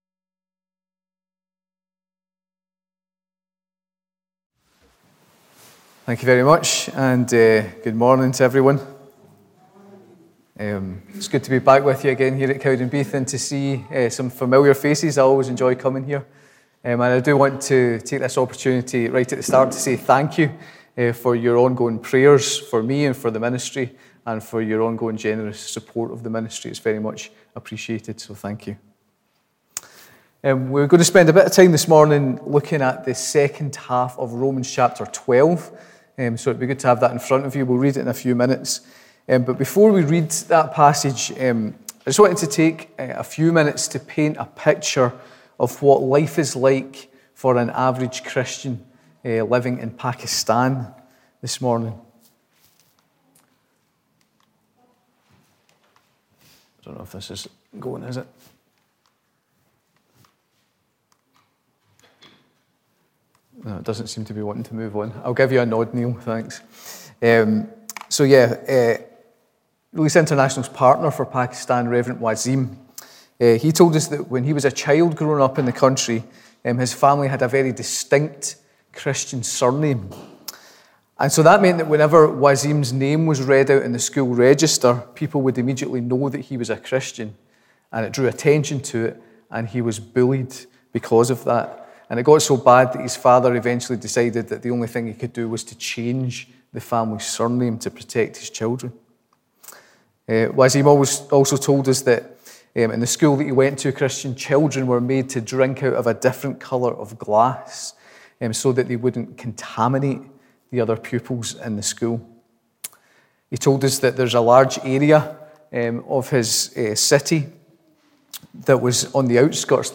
Morning Service 12th June 2022